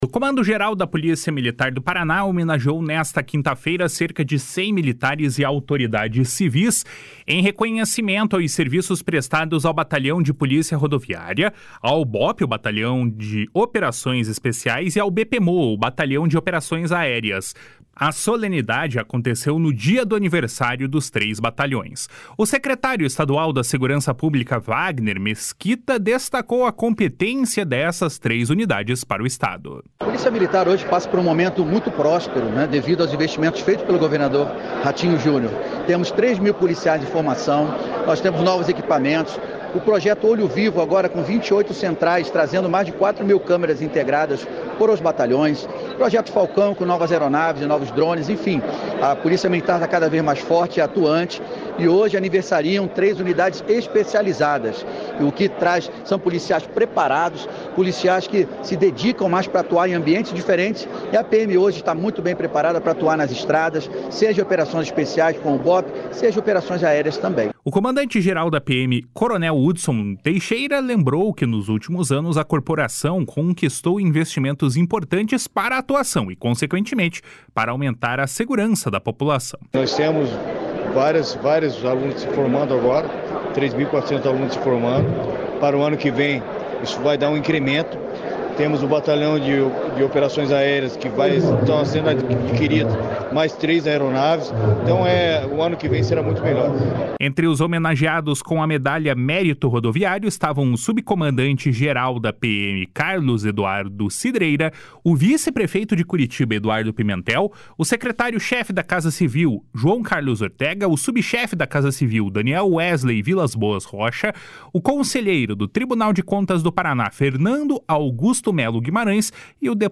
A solenidade aconteceu no dia do aniversário dos três batalhões. O secretário estadual da Segurança Pública, Wagner Mesquita, destacou a competência dessas três unidades para o Estado.
O comandante-geral da PMPR, coronel Hudson Leôncio Teixeira, lembrou que, nos últimos anos, a corporação conquistou investimentos importantes para a atuação e, consequentemente, para aumentar a segurança da população.